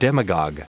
Prononciation du mot demagog en anglais (fichier audio)